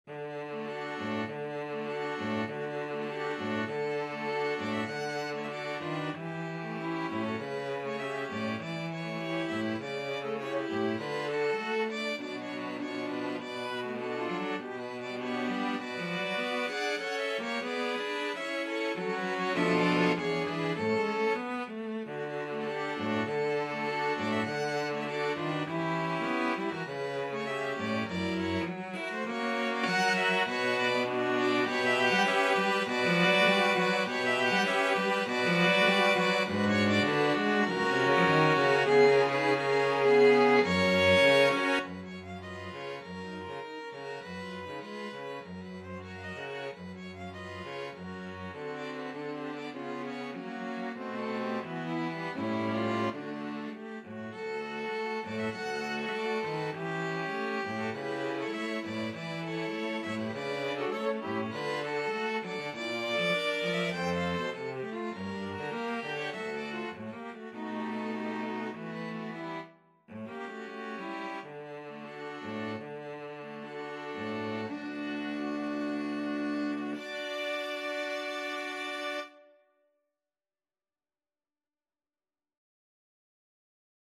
Classical Isaac Albéniz Tango Op.165 No.2 from Espana String Quartet version
Free Sheet music for String Quartet
Violin 1Violin 2ViolaCello
D major (Sounding Pitch) (View more D major Music for String Quartet )
2/4 (View more 2/4 Music)
Andantino (View more music marked Andantino)
Classical (View more Classical String Quartet Music)